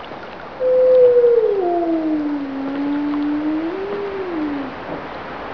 Wildlife Sounds
wolfhowl5.wav